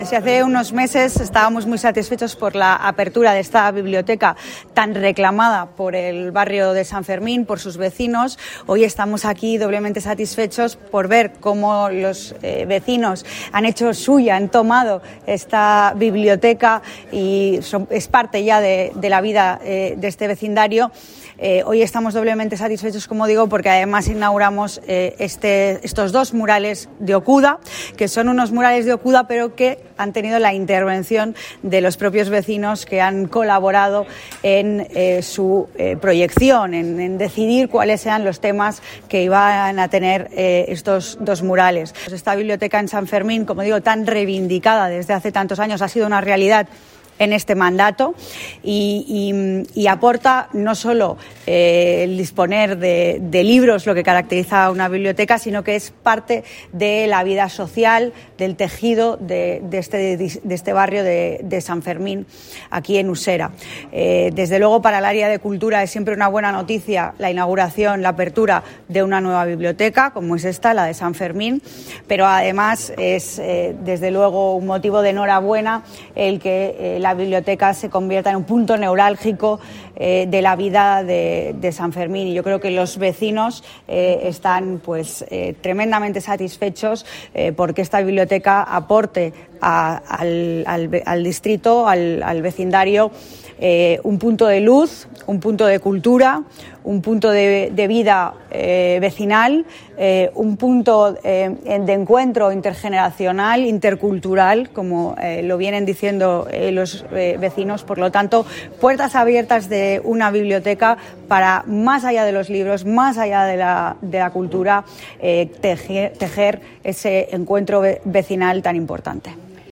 Nueva ventana:Andrea Levy, delegada de Cultura, Turismo y Deporte
Levy en la inauguración de mural de Okuda.mp3